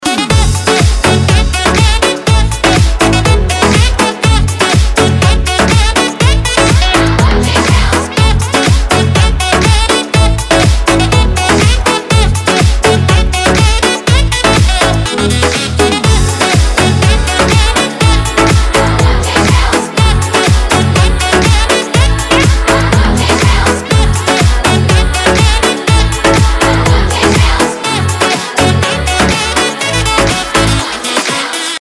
• Качество: 320, Stereo
Club House
без слов
Саксофон
Saxophone